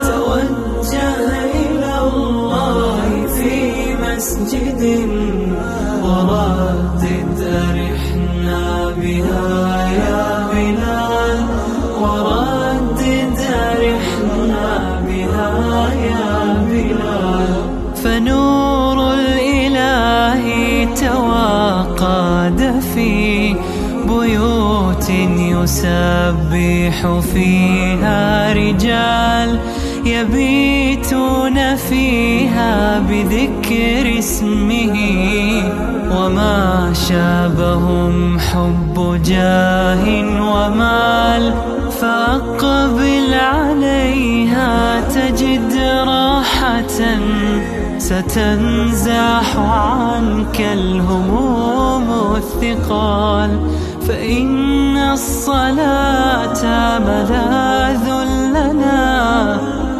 Golf R taillights start up sound effects free download